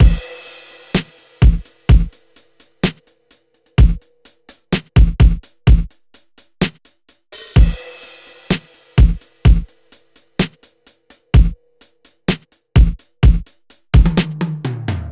14 drums A1.wav